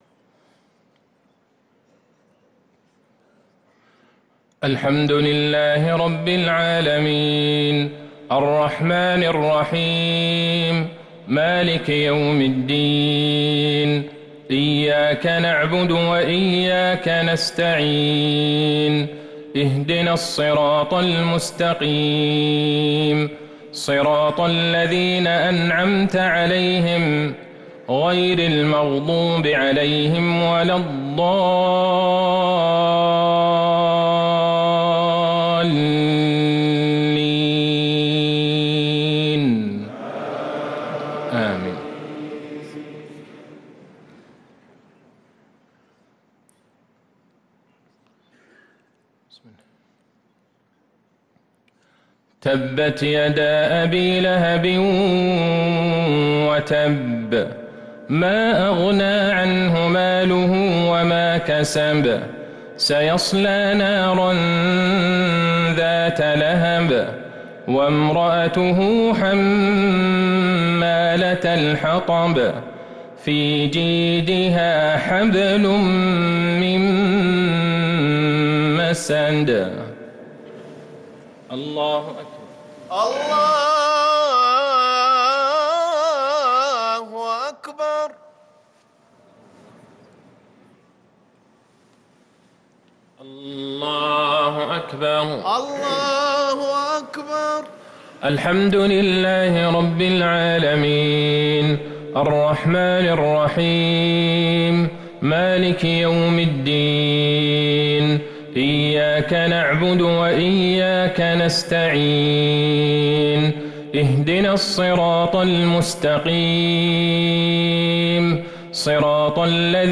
صلاة المغرب للقارئ عبدالله البعيجان 1 ربيع الآخر 1445 هـ
تِلَاوَات الْحَرَمَيْن .